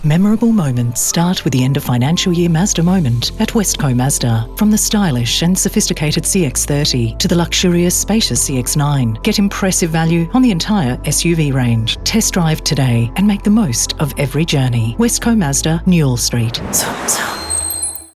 Female
Radio Commercials
Words that describe my voice are Natural, Warm, Australian Female Voice actor.